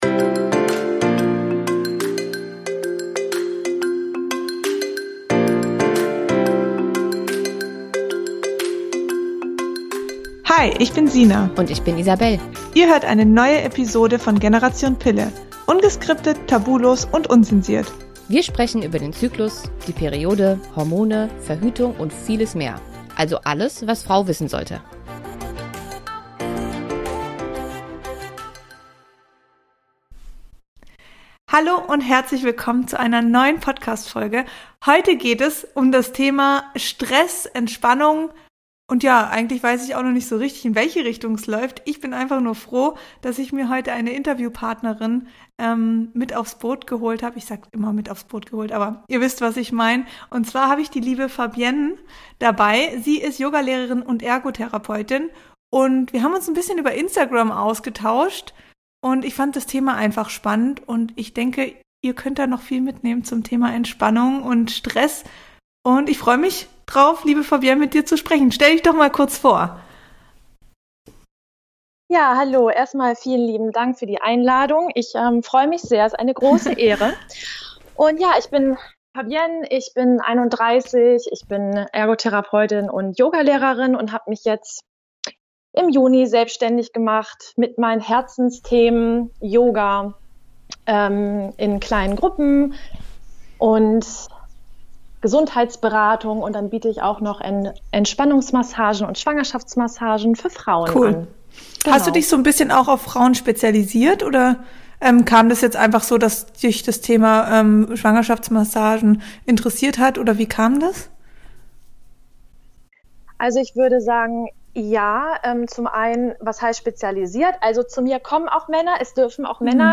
Tipps zur Entspannung: im Interview